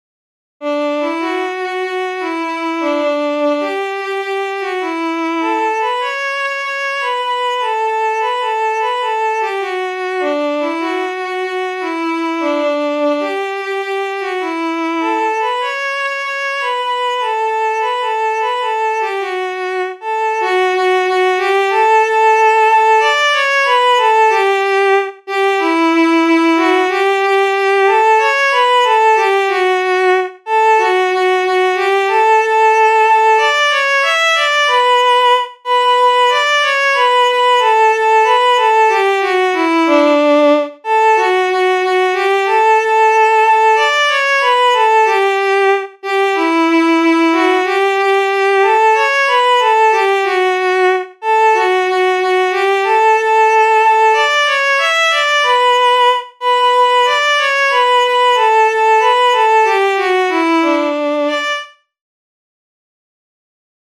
Aquests de la capa negra - Sardana curta